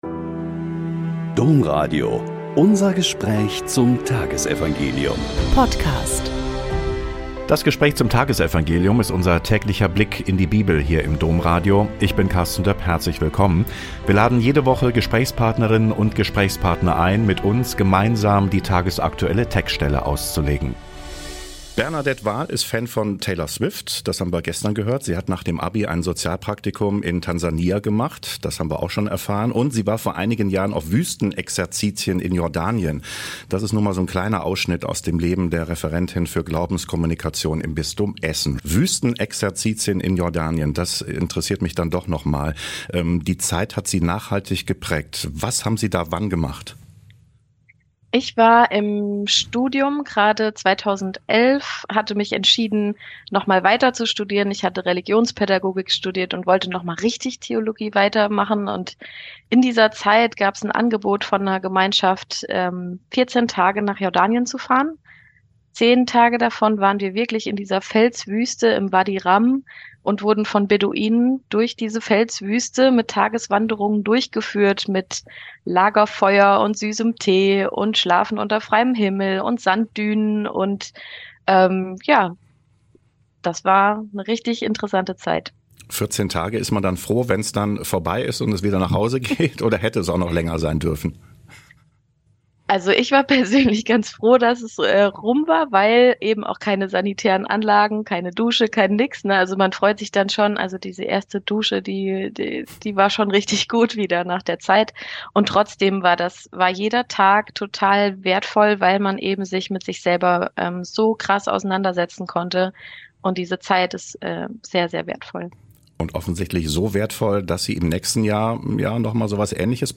Joh 19,31-37 - Gespräch